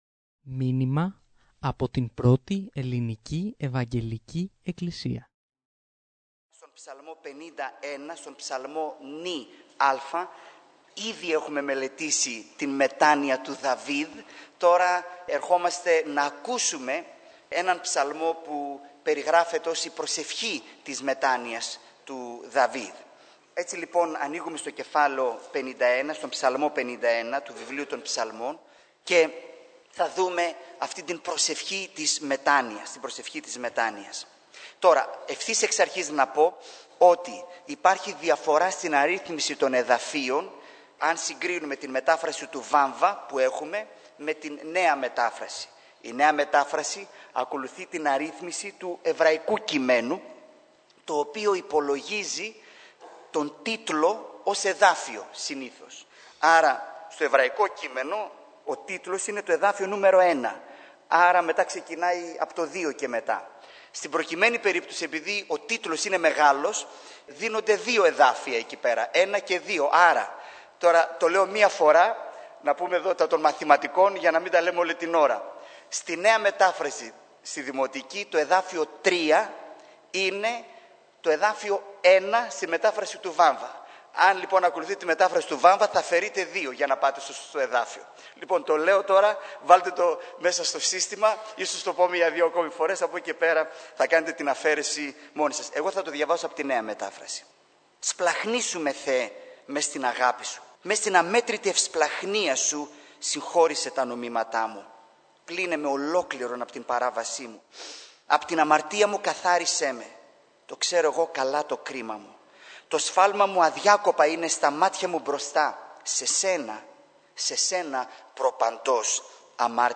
Κυρήγματα | AEEE